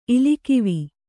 ♪ ili kivi